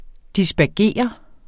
dispergere verbum Bøjning -r, -de, -t Udtale [ disbæɐ̯ˈgeˀʌ ] Oprindelse fra latin dispergere 'adsprede', af dis- og spargere 'strø, sprede' Betydninger 1.